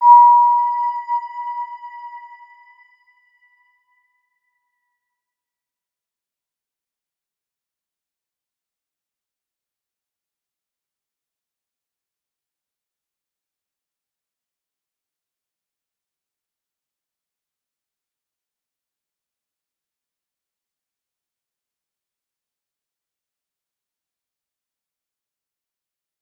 Round-Bell-B5-p.wav